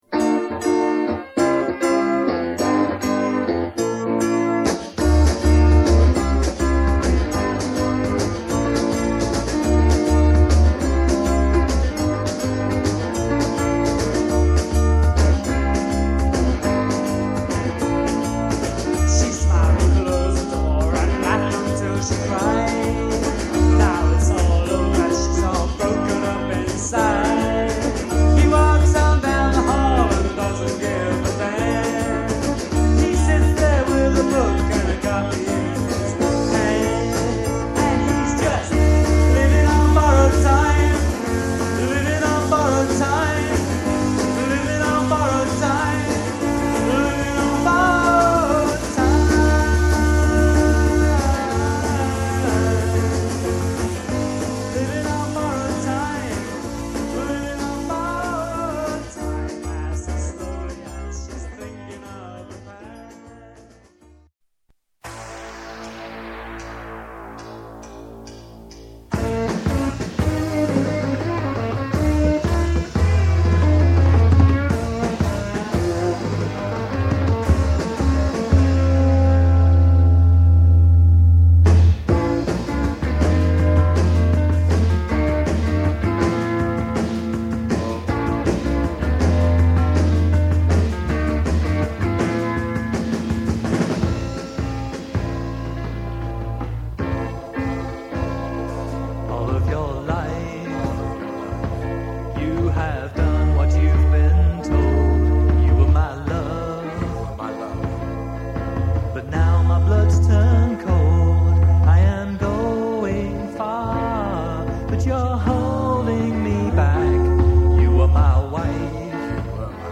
Sample Medley